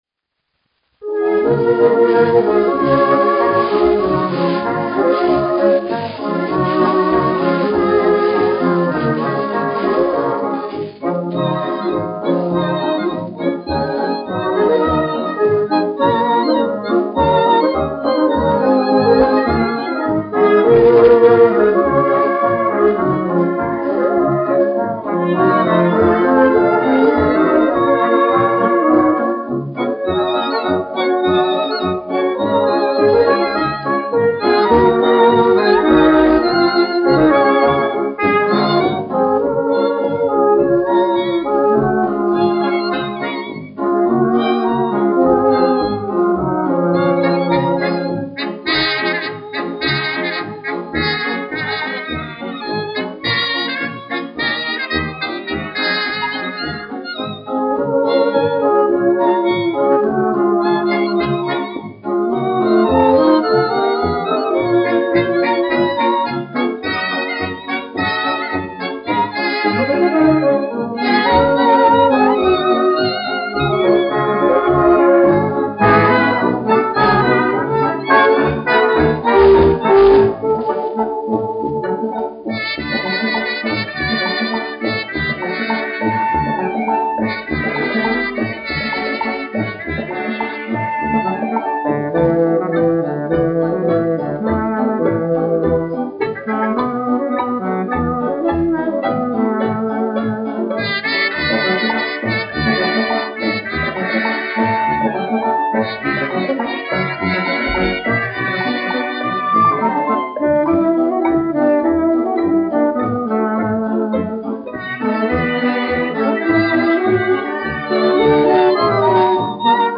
1 skpl. : analogs, 78 apgr/min, mono ; 25 cm
Fokstroti
Kinomūzika
Latvijas vēsturiskie šellaka skaņuplašu ieraksti (Kolekcija)